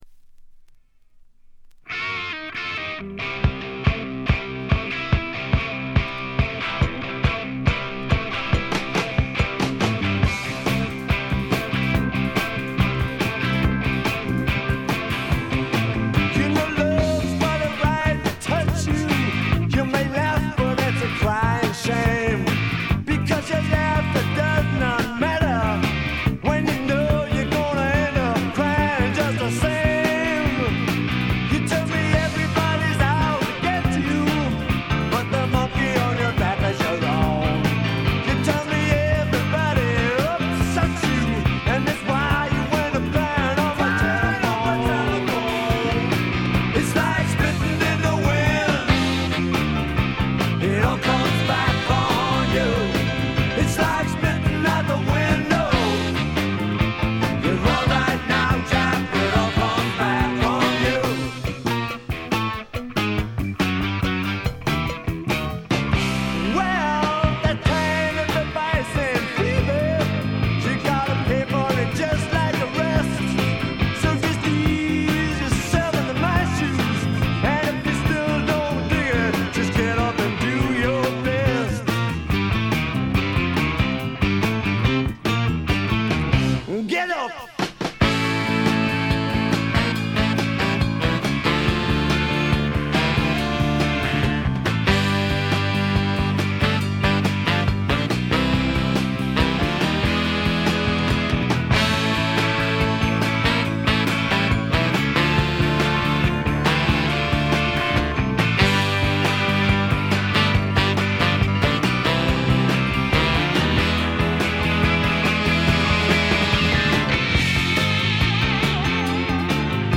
軽いチリプチ少々。
パブロック風味満載、いぶし銀の英国フォークロックです。
試聴曲は現品からの取り込み音源です。